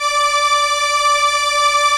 Index of /90_sSampleCDs/Keyboards of The 60's and 70's - CD1/STR_ARP Strings/STR_ARP Solina